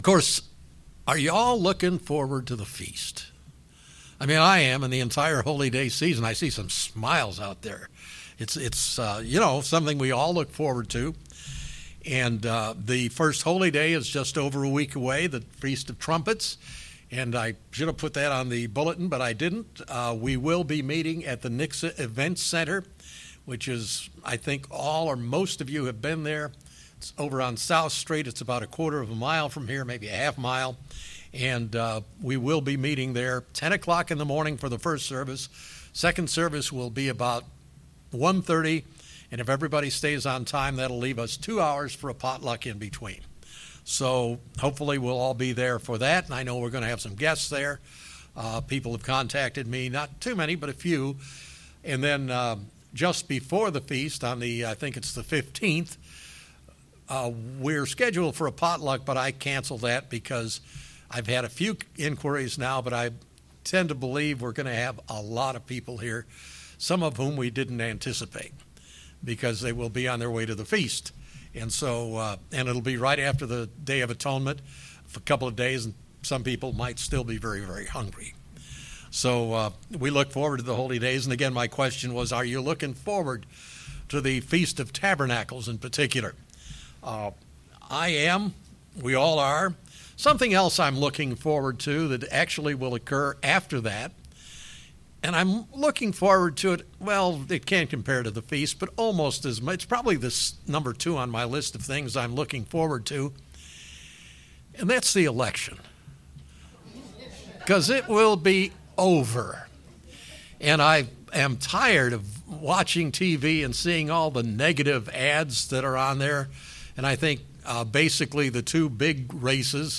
As a Christian in today's world, what should our role be in politics? In this sermon, the speaker looks into the topic of politics and just what are role needs to be.